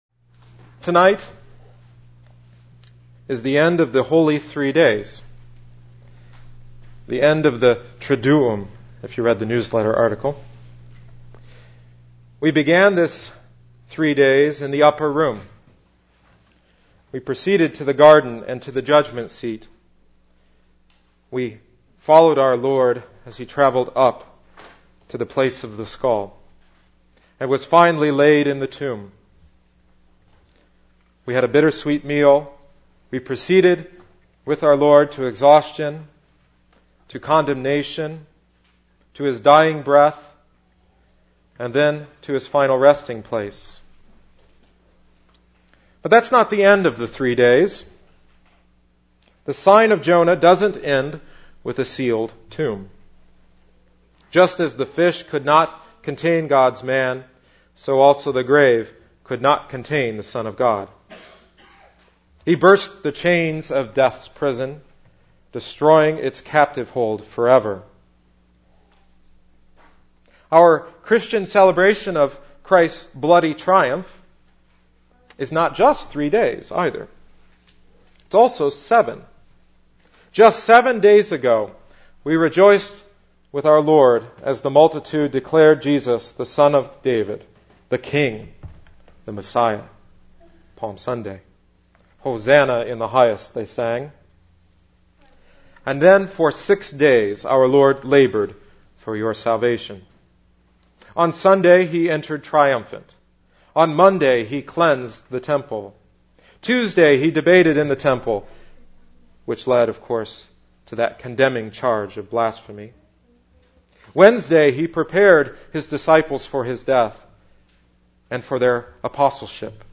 in Sermons |
Easter Vigil